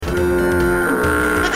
Spongebob Fail Sound Button - Bouton d'effet sonore